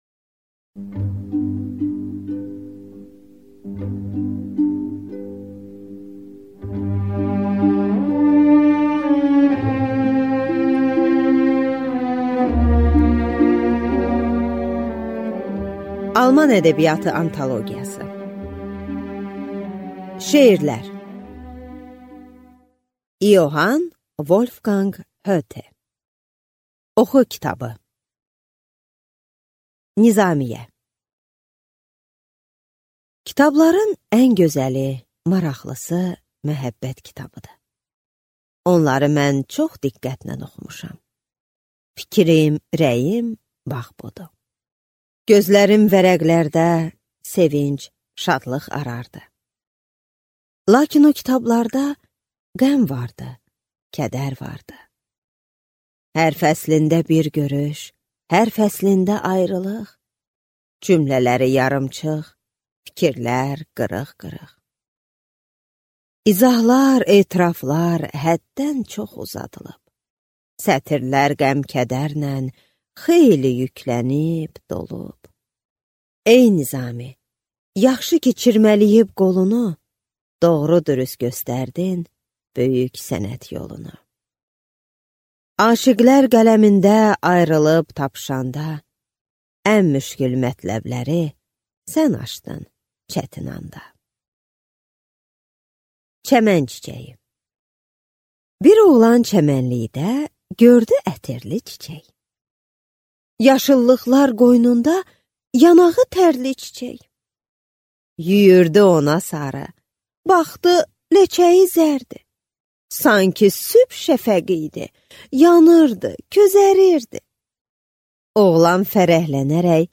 Аудиокнига Alman ədəbiyyatı antalogiyası | Библиотека аудиокниг